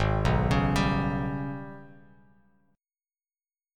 G#mM11 Chord